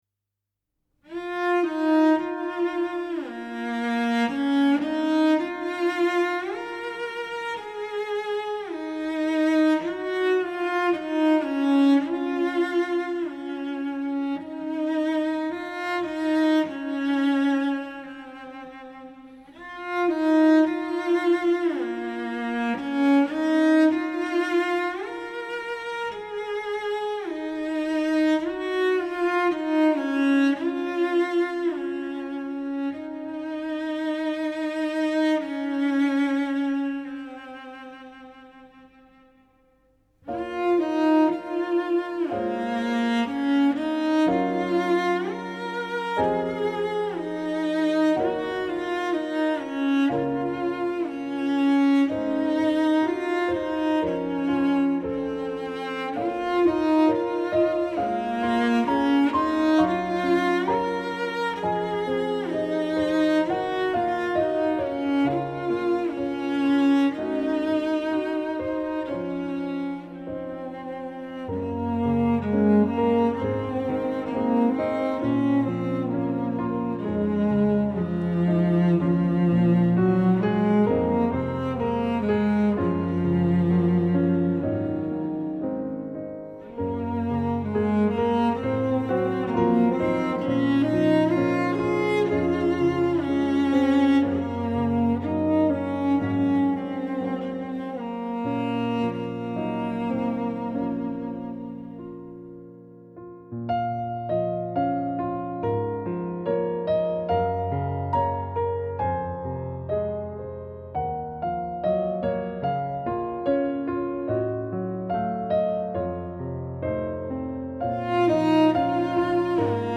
レコーディングは各自の自宅録音、そして私の小さなスタジオで行っています。
Download Piano
Composed & Cello
どこか果てしなく静かなイメージ.....宇宙のようなものでしょうか。